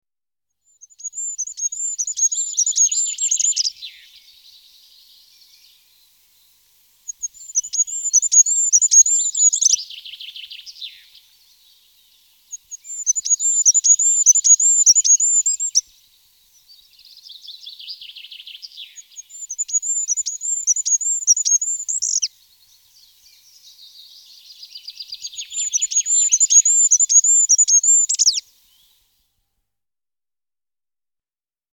die genannten und abgebildeten Vögel sind im Park anzutreffen
Wintergoldhähnchen
Wintergoldhaehnchen.mp3